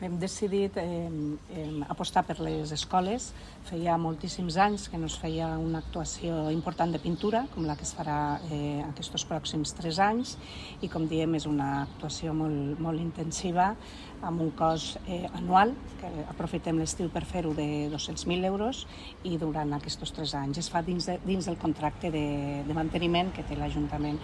Talls de veu
Consulta els talls de veu (en format MP3) de l'Alcalde i dels diferents regidors de l'Ajuntament de Lleida